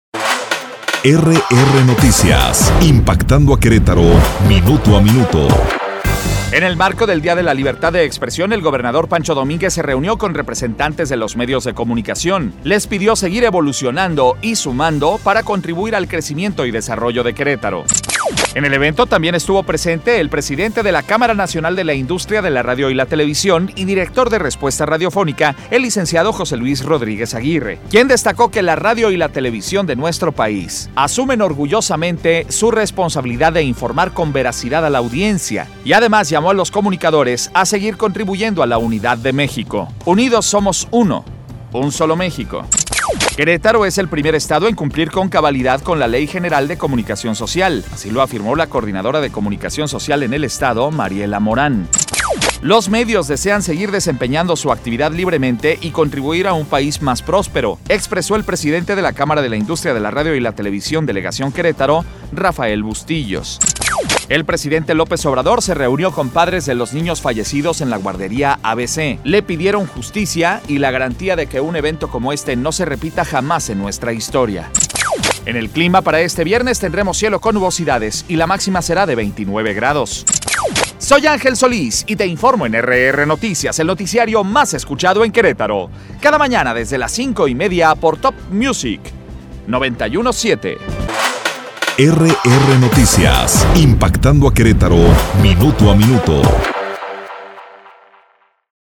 Resumen Informativo 7 de junio de 2019